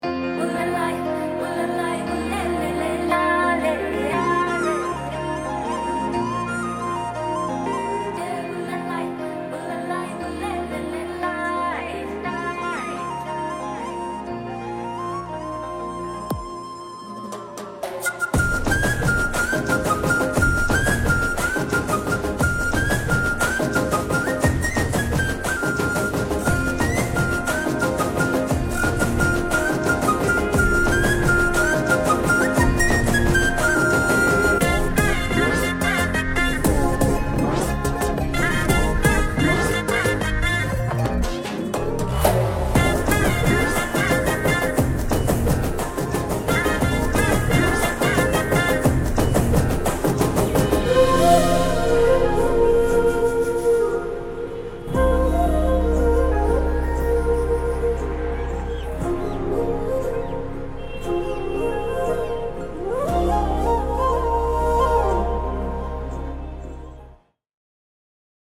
without dialogues and disturbances